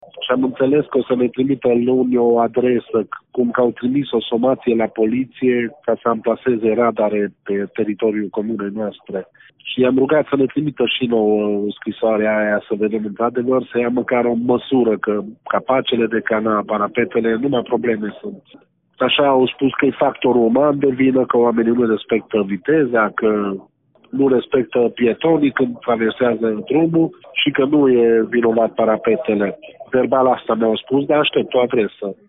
Primarul Sofalvi Sazbolcs.